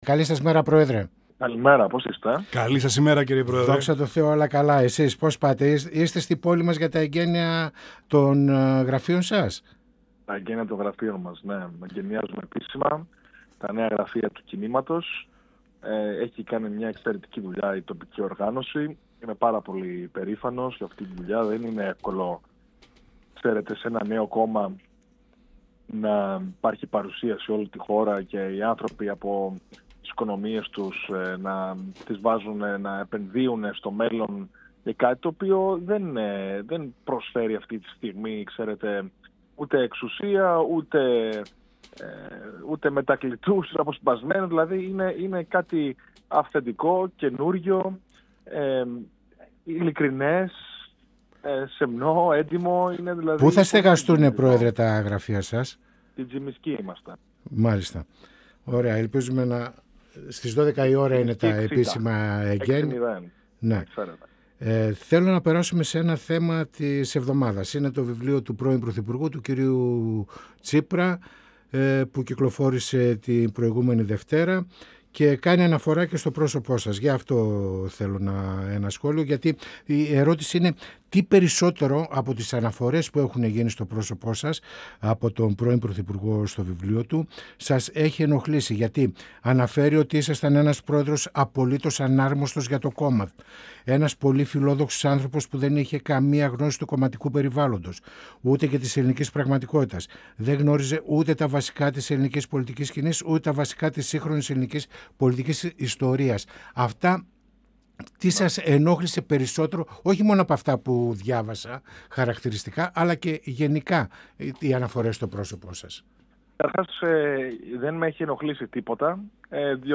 Εφ΄ όλης της ύλης συνέντευξη στην εκπομπή «Πανόραμα Επικαιρότητας» του 102FM της ΕΡΤ3 παραχώρησε ο Πρόεδρος του «Κινήματος Δημοκρατίας» Στέφανος Κασσελάκης. Ειδικότερα ο κ. Κασσελάκης σχολίασε τις αναφορές που γίνονται στο πρόσωπό του στο βιβλίο του Αλέξη Τσίπρα «Ιθάκη», στις ενδεχόμενες αναγκαίες συνεργασίες μεταξύ κομμάτων, μετά τις επόμενες βουλευτικές εκλογές, καθώς και στα σκάνδαλα διαφθοράς που συνδέονται με τη σημερινή κυβέρνηση Μητσοτάκη.